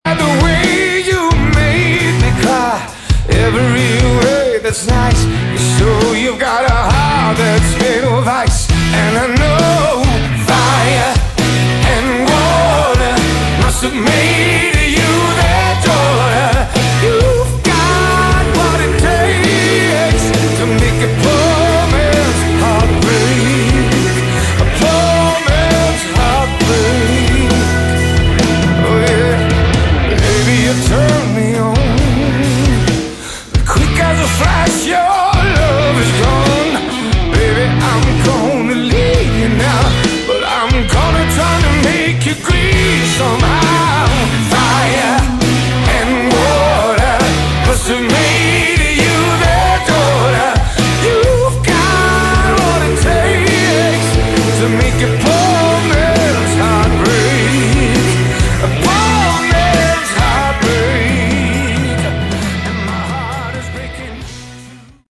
Category: Melodic Rock
vocals, guitar
bass
drums